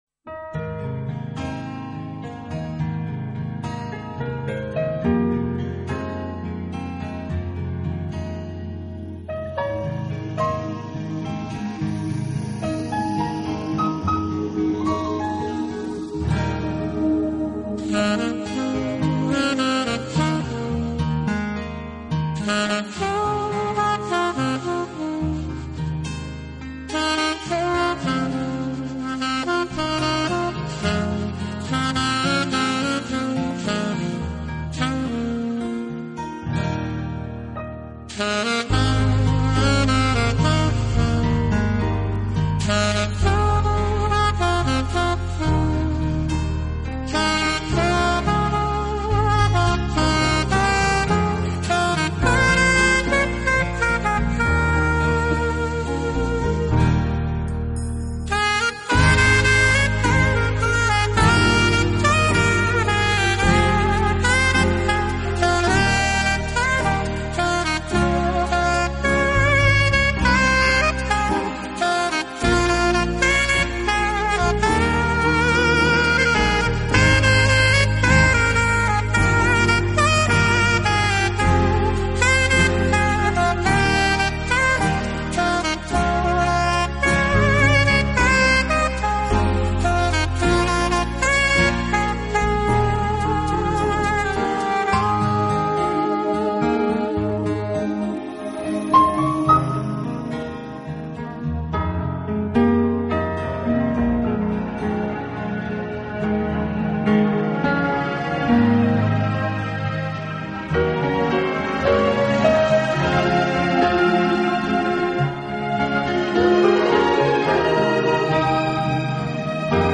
我的爱因你而存在缠绵的萨克斯乐曲，演绎浪漫的情人物语，
这丝丝情意像恋人般彼此依偎，轻轻地，静静地直到永远！